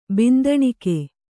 ♪ bindaṇike